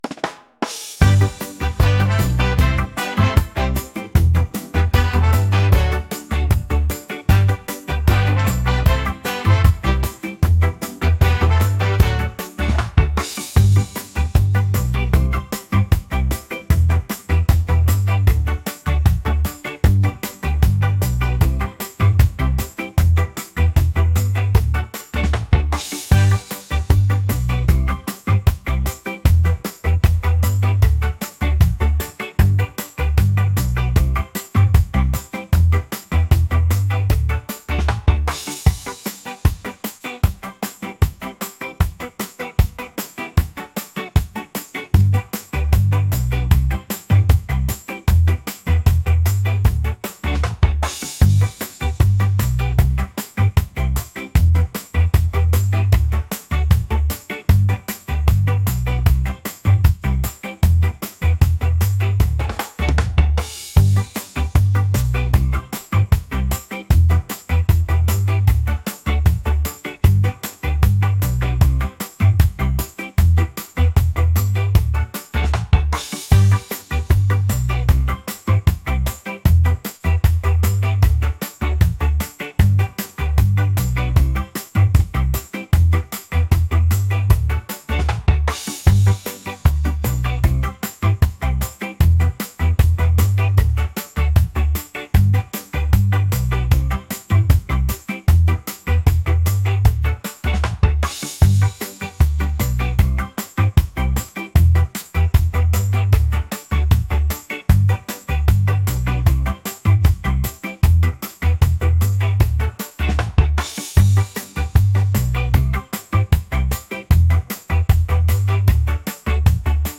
upbeat | catchy | reggae